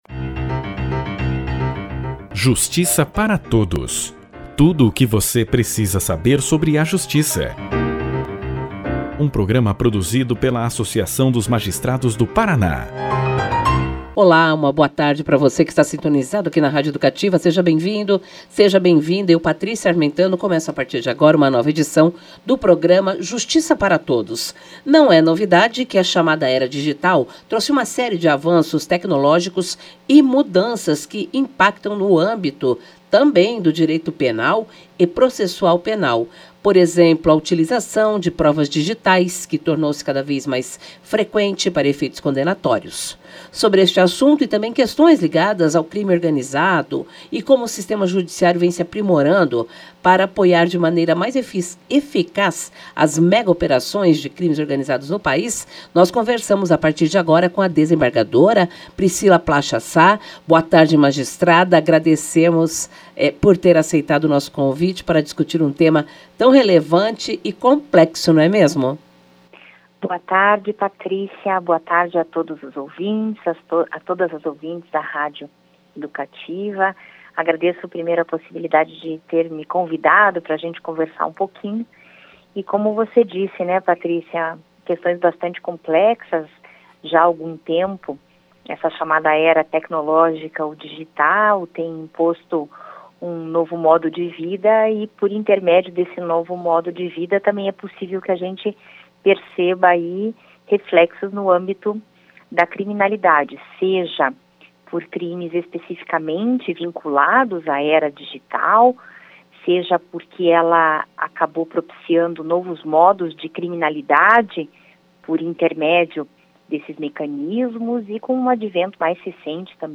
Entrevistada pelo programa de Rádio da AMAPAR, o Justiça Para Todos, a desembargadora Priscilla Placha Sá, falou sobre a atuação do Judiciário no combate ao crime organizado no país.